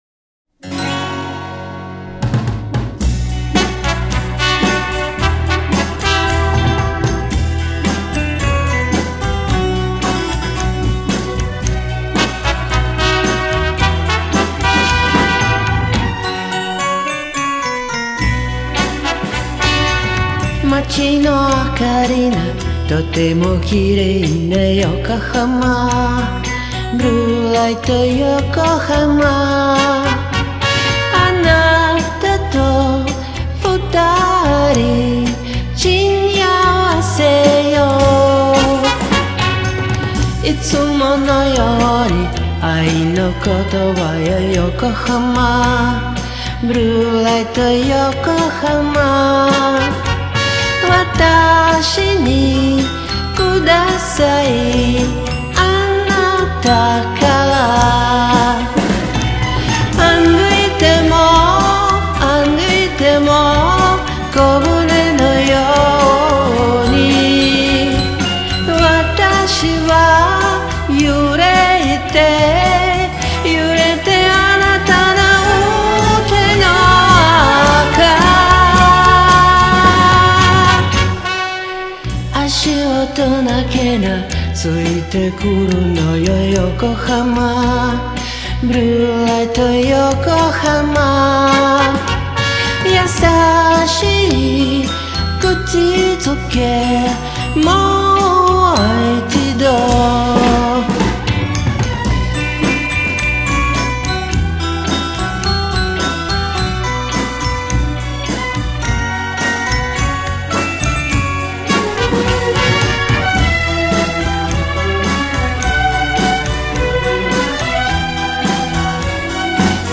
Как отрывок из радиопрограммы... из того времени...